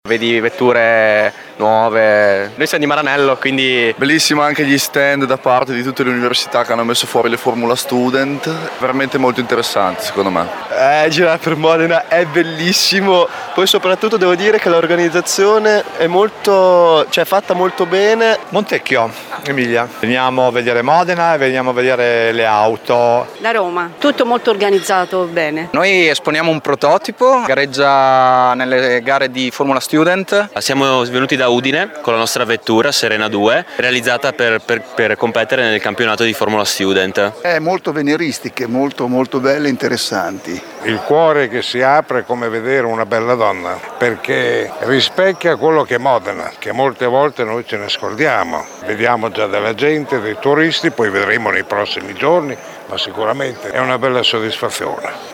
Le interviste ai protagonisti:
VOX-MOTOR-VALLEY.mp3